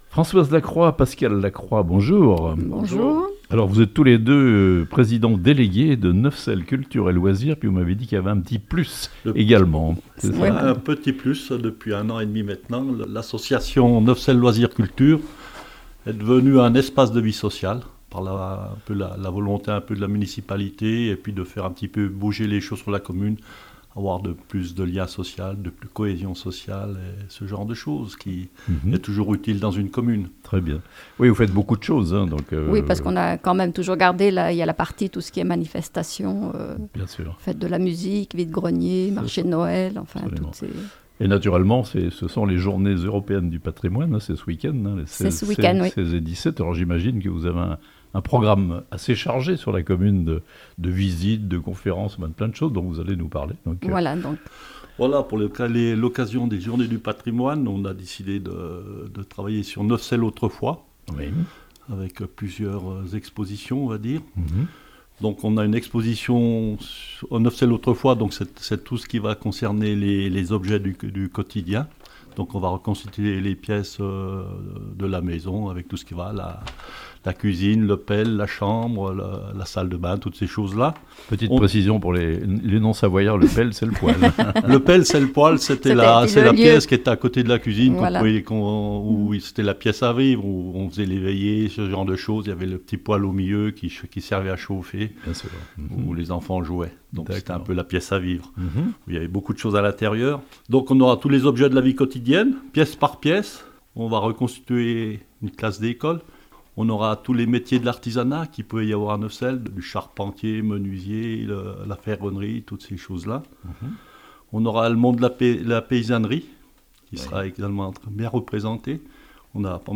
A Neuvecelle, les Journées Européennes du Patrimoine jouent les prolongations jusqu'au 23 septembre (interviews)